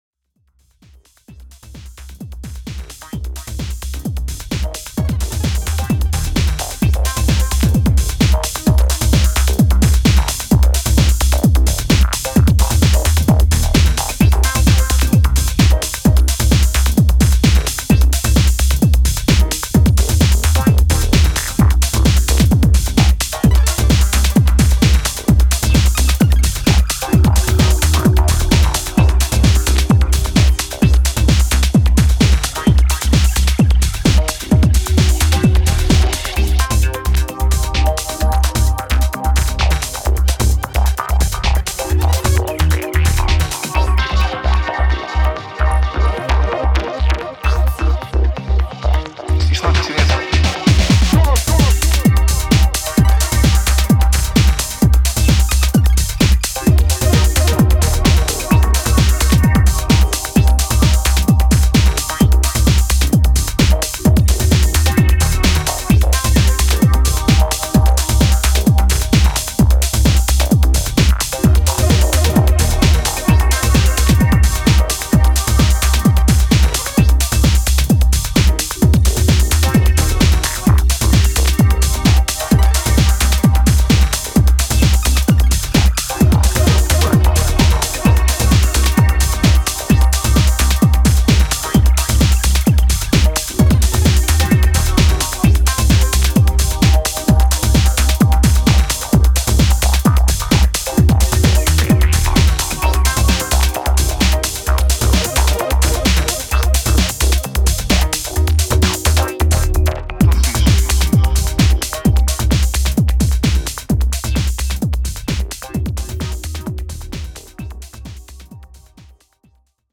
いずれの楽曲からもそれぞれの個性が溢れており、広範なタイプのミニマル・ハウスを収録した一枚です！